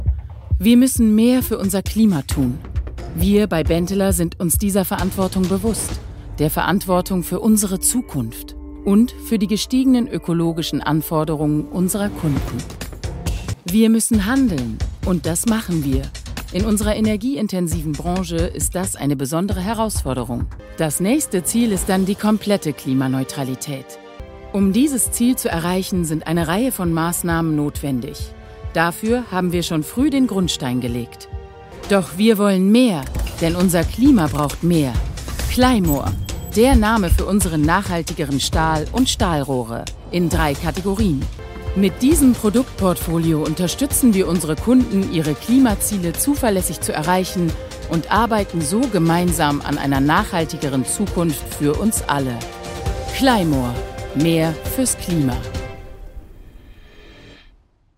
dunkel, sonor, souverän, markant, sehr variabel
Mittel minus (25-45)
Commercial (Werbung)